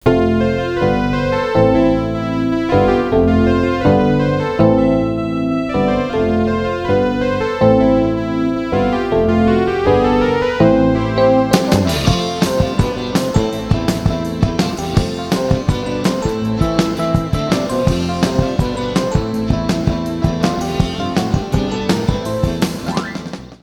Pop Rock. Introducción musical.
pop
melodía
rock